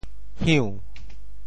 朽 部首拼音 部首 木 总笔划 6 部外笔划 2 普通话 xiǔ 潮州发音 潮州 hiun2 白 中文解释 朽 <形> (形声。